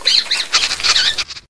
sniff.ogg